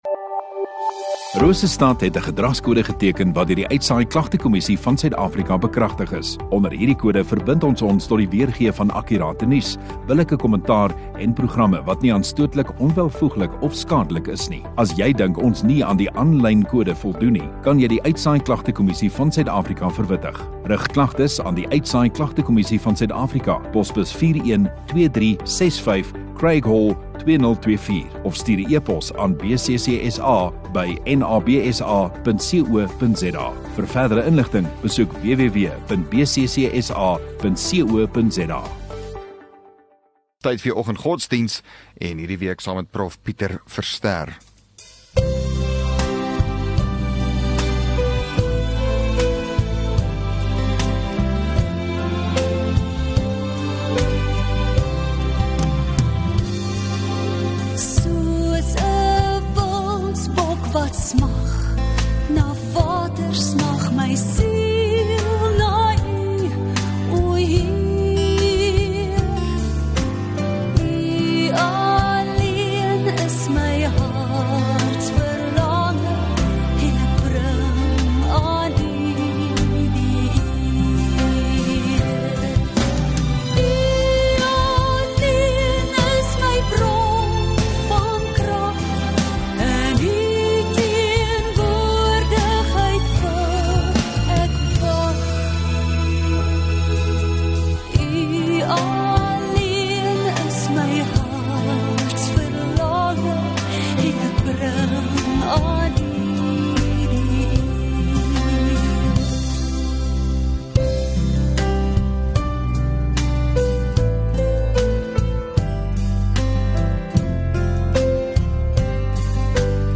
2 May Vrydag Oggenddiens